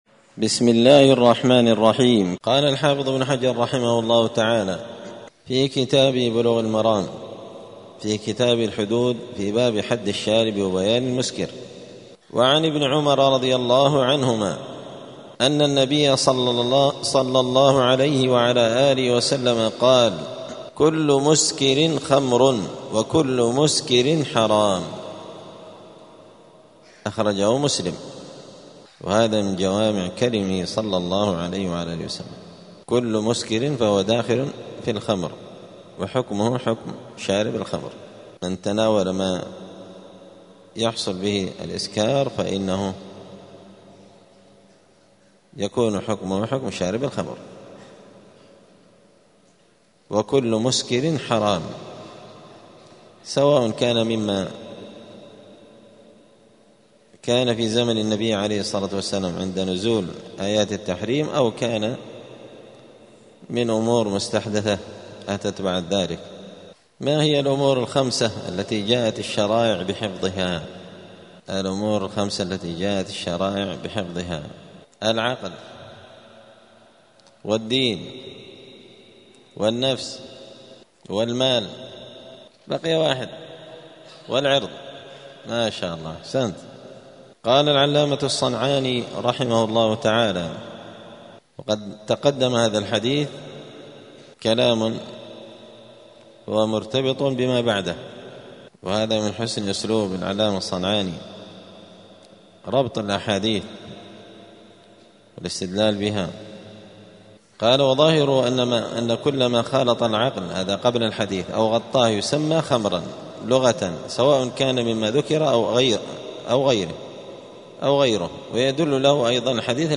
*الدرس الرابع والثلاثون (34) {باب حد الشارب الخمر الخمر ماخامر العقل}*